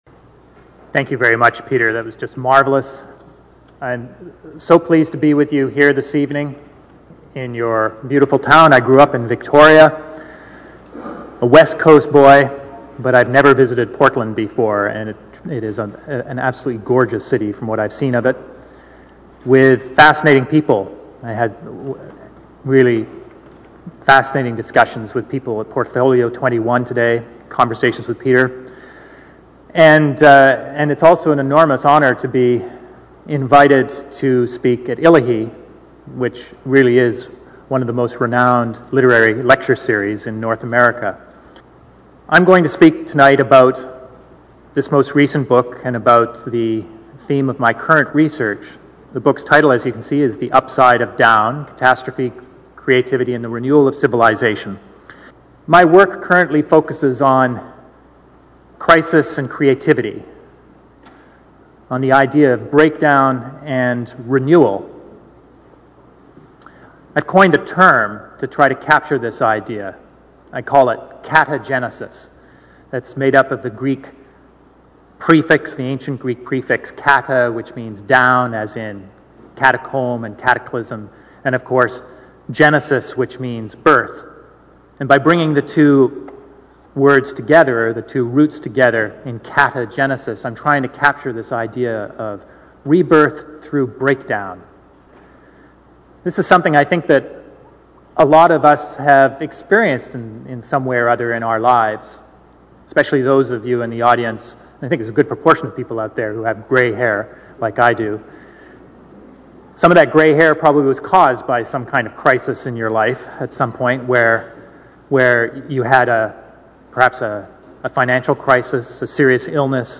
------ Here we feature episodes that feature incisive interviews, speeches, thoughts, opinion and convergences. ------